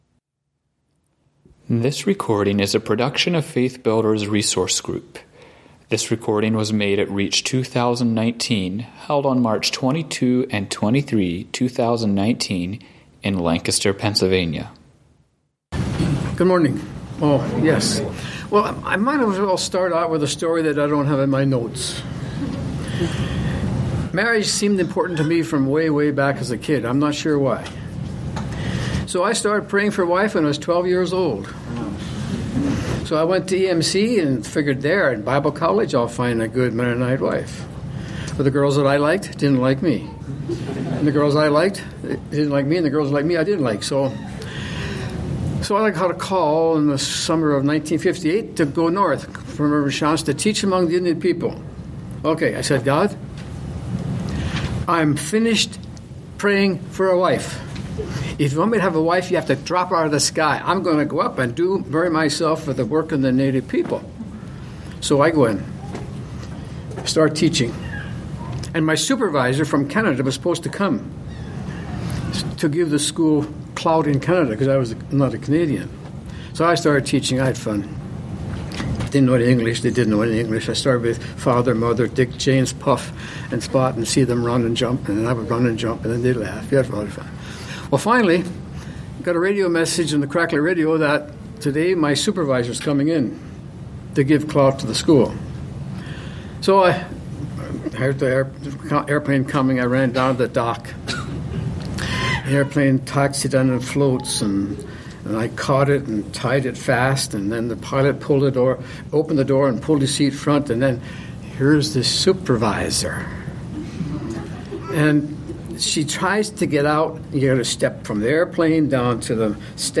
Home » Lectures » How to Make the Most of Husband & Wife Relationships